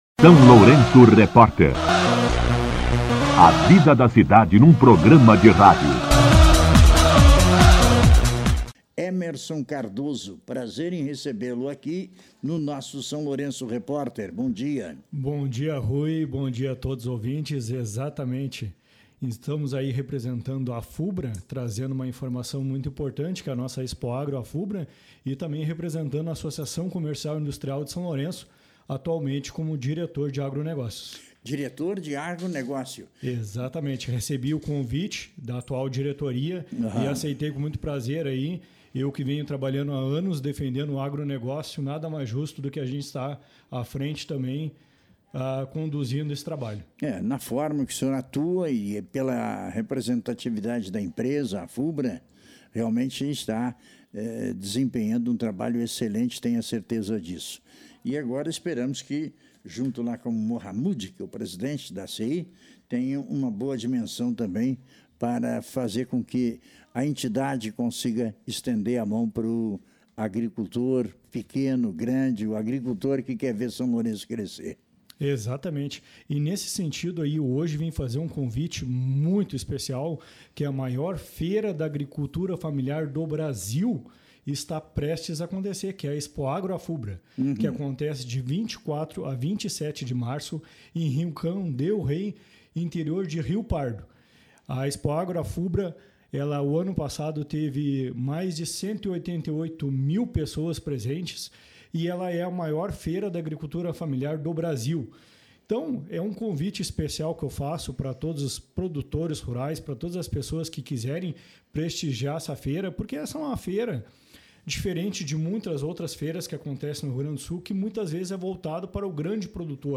entrevista-11.03-afubra.mp3.mp3